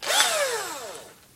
机器 " 螺丝刀 1
Tag: 呼呼 嗡嗡声 电机 机械 螺丝刀 机械 工具 钻头